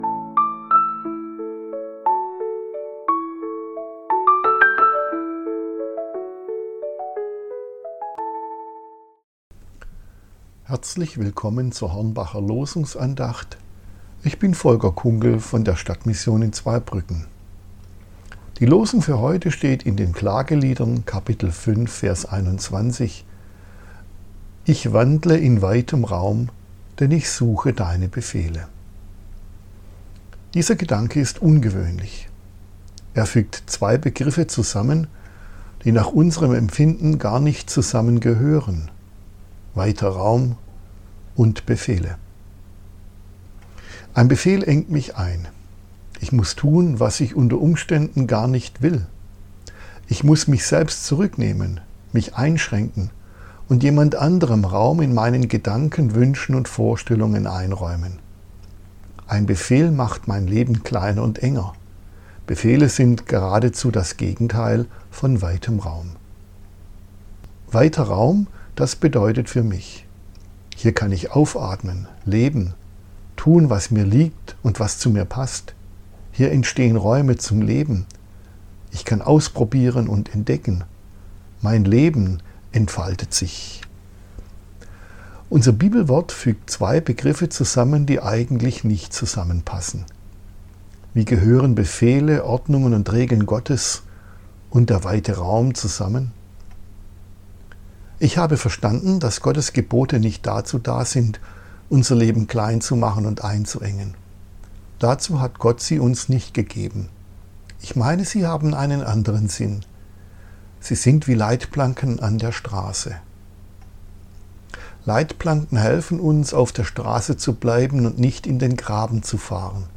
Losungsandacht für Donnerstag, 25.12.2025 – Prot.